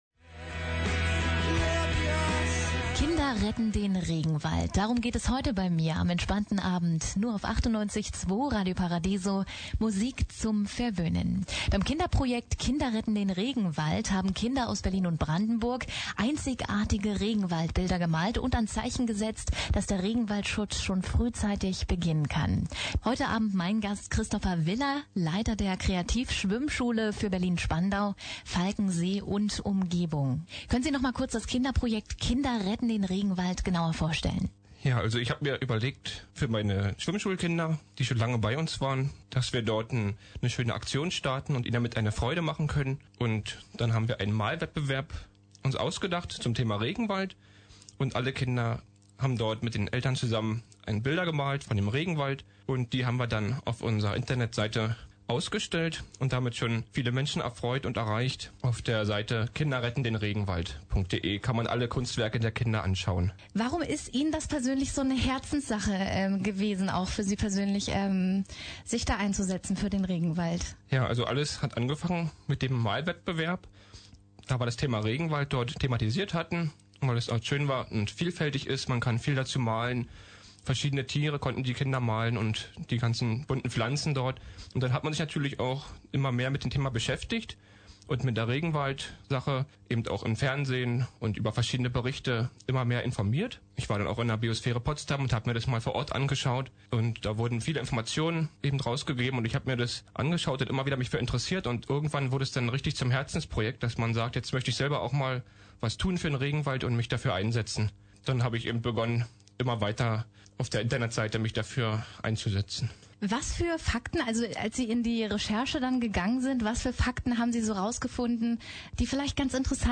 Quelle: Studioauftritt Radio-Paradiso vom Juli 2013
Radio_Paradiso_Studioauftritt_kinder_retten_den_regenwald-Online.mp3